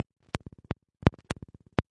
现场点击率 " 乙烯基环形卷
描述：乙烯基的门控声音安排在打击乐循环中 所有录音96.000赫兹＆amp; 24位
Tag: 冲击 的PERC 即兴 乙烯基 打击乐器 经编 敲击循环 量化 鼓环 常规